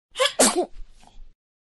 دانلود آهنگ عطسه 3 از افکت صوتی انسان و موجودات زنده
دانلود صدای عطسه 3 از ساعد نیوز با لینک مستقیم و کیفیت بالا
برچسب: دانلود آهنگ های افکت صوتی انسان و موجودات زنده دانلود آلبوم انواع صدای عطسه – مرد و زن از افکت صوتی انسان و موجودات زنده